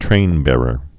(trānbârər)